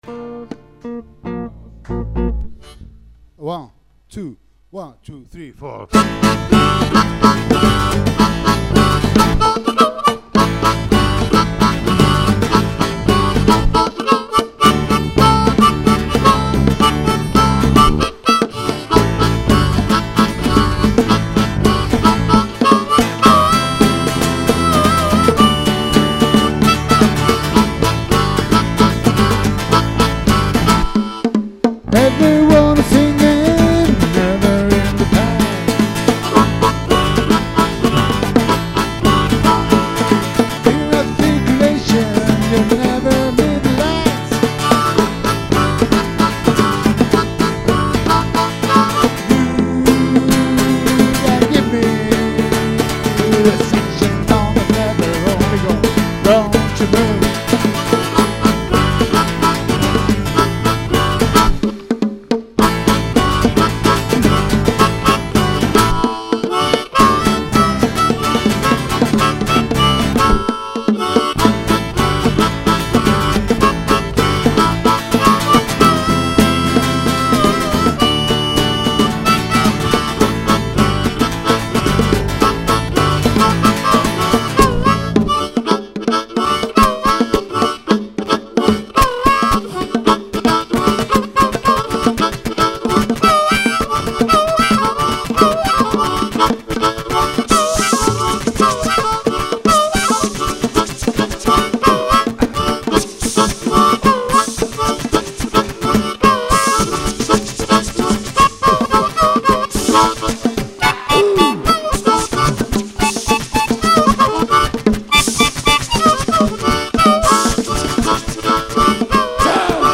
Armónica, ruidos y voz
Bajo y ruidos
Bongos
Guitarra acústica y ruidos